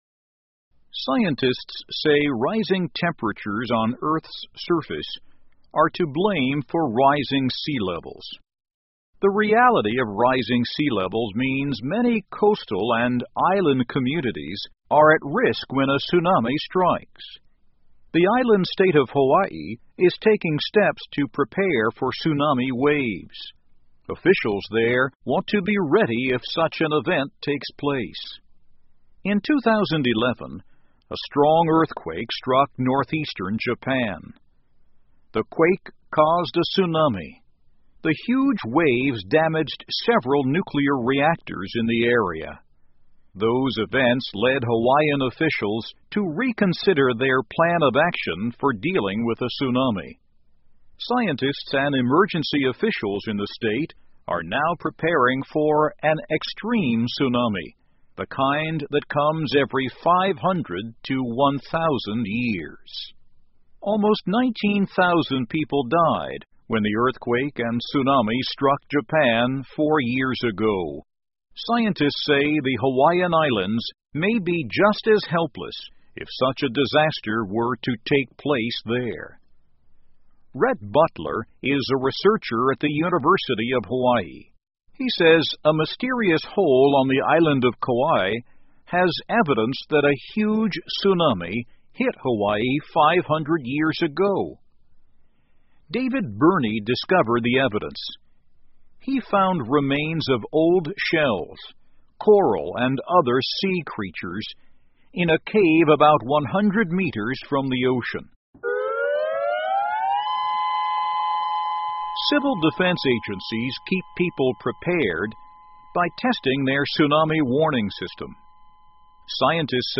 VOA慢速英语夏威夷为潜在海啸做准备 听力文件下载—在线英语听力室